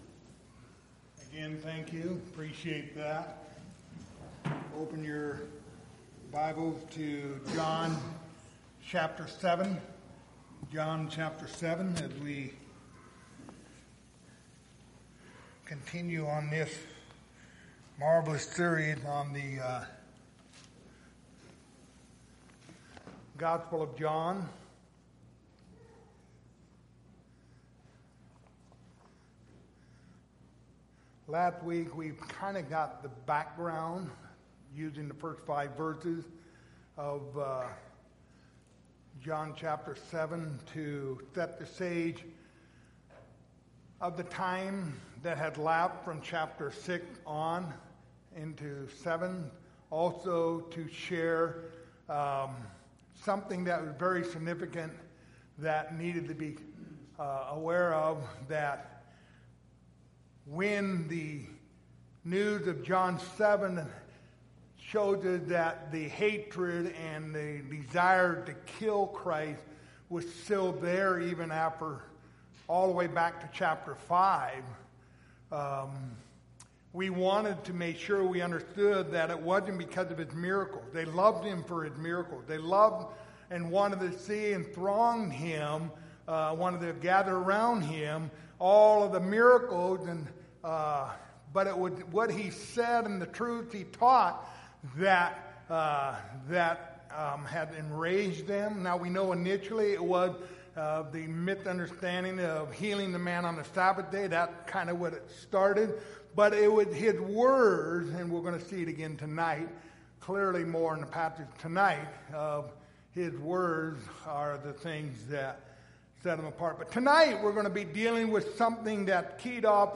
Passage: John 7:6-9 Service Type: Wednesday Evening